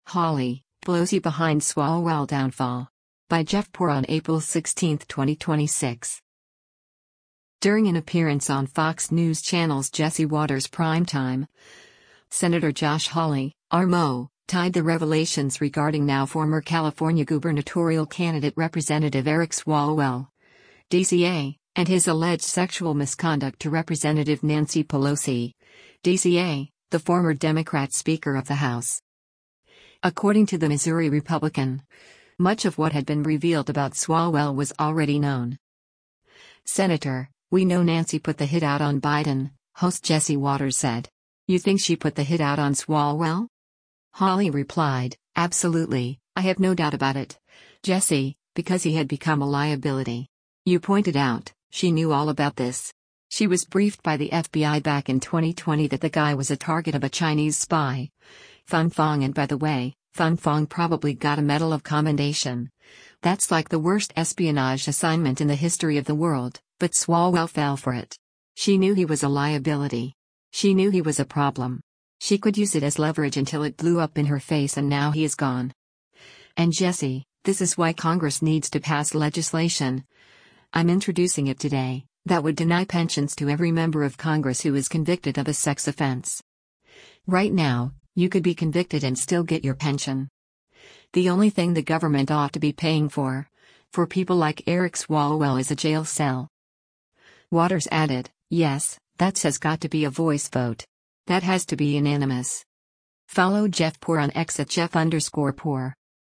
During an appearance on Fox News Channel’s “Jesse Watters Primetime,” Sen. Josh Hawley (R-MO) tied the revelations regarding now-former California gubernatorial candidate Rep. Eric Swalwell (D-CA) and his alleged sexual misconduct to Rep. Nancy Pelosi (D-CA), the former Democrat Speaker of the House.